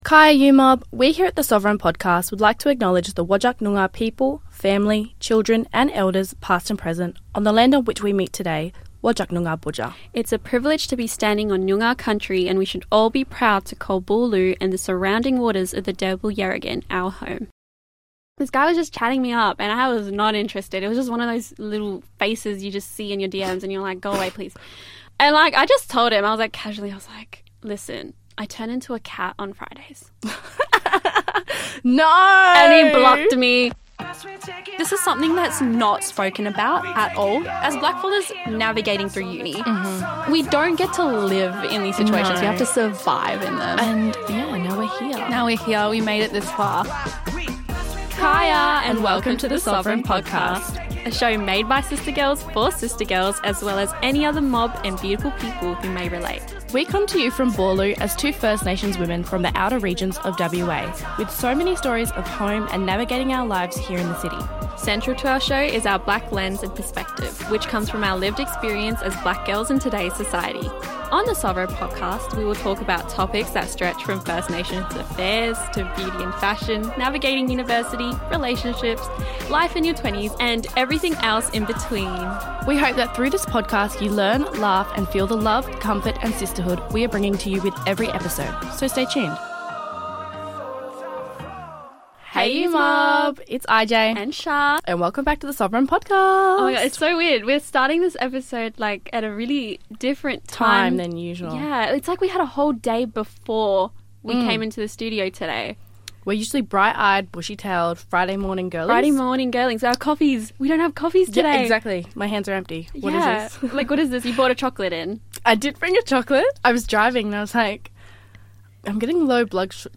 in the big red studio